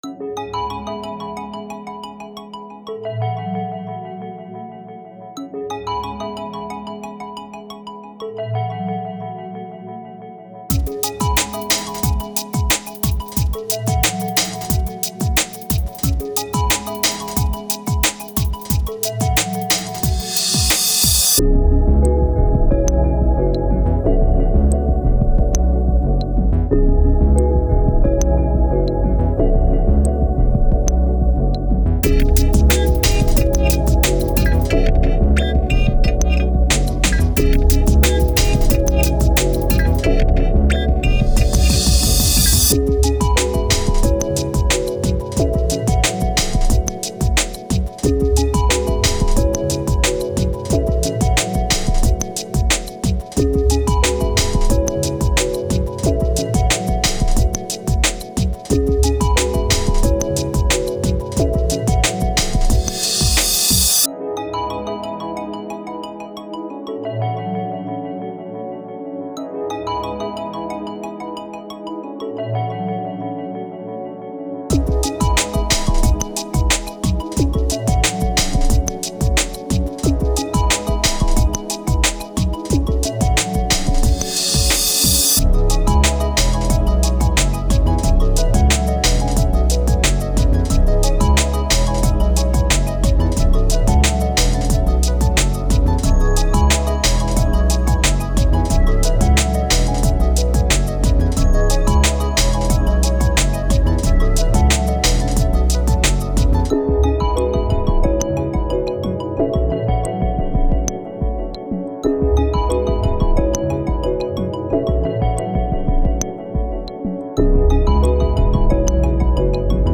IDM'n Bass
そんな完結作は静かめな雰囲気でまとめてみたよ。